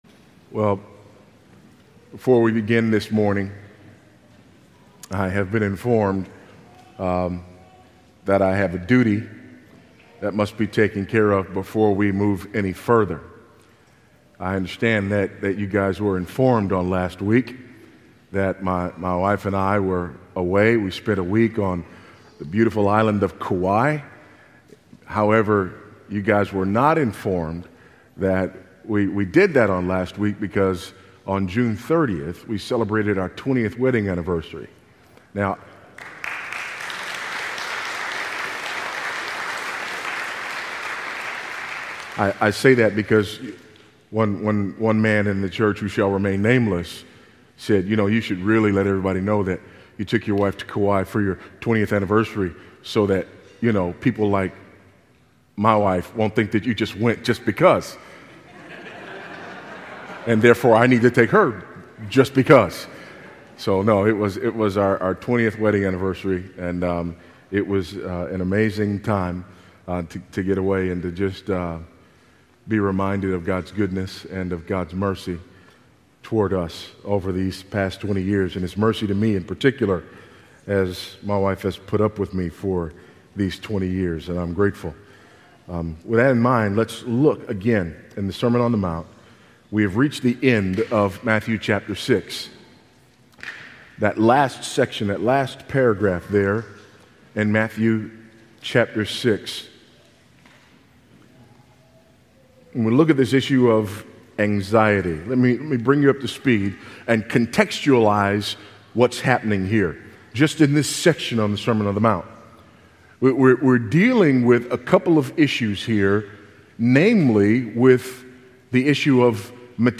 In this sermon, Voddie Baucham unfolds this timeless truth with clarity and pastoral urgency. He challenges believers to relinquish the world’s frantic pursuit of comfort, success, and security, and instead to embrace Kingdom living — a life grounded in Scripture, shaped by Christlike righteousness, and anchored in eternal purpose rather than temporal gain.